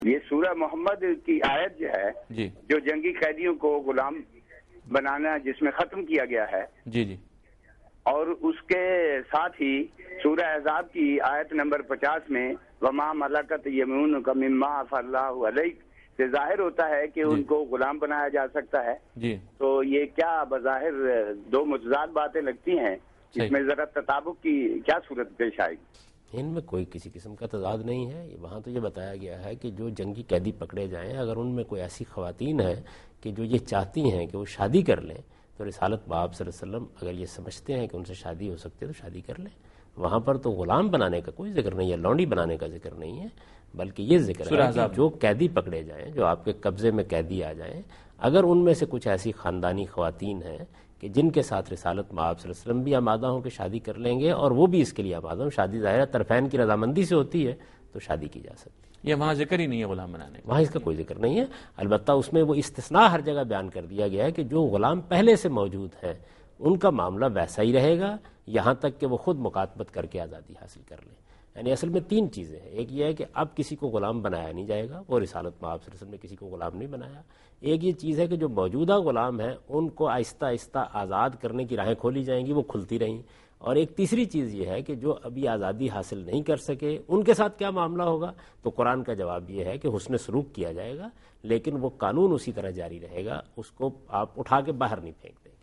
Category: TV Programs / Dunya News / Deen-o-Daanish /
Javed Ahmad Ghamidi Answer the Question on Islamic Commandments about Prisoners of War In Program Deen o Danish
دین ودانش کے اس پروگرام میں جاوید احمد صاحب غامدی جنگی قیدیوں سے متعلق قران کا حکم سے متعلق سوال کا جواب دے رہے ہیں